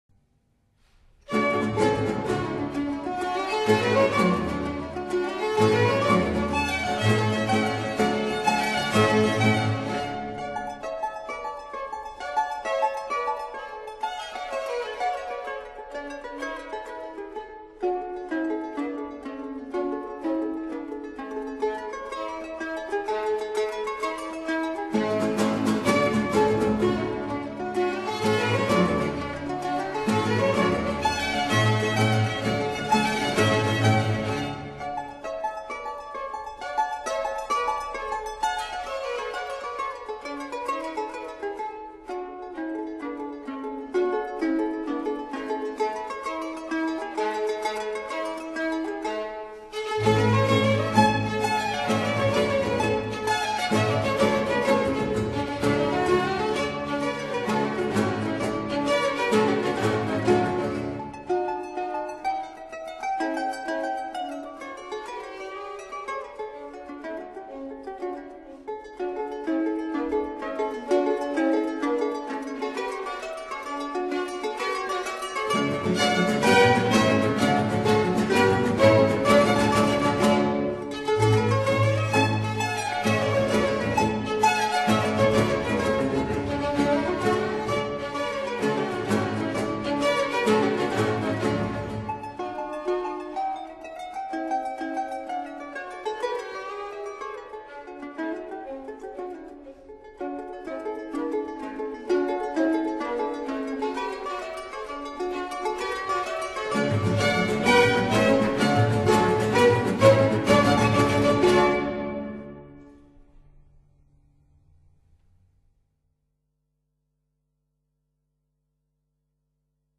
Concerto for Mandolin in D major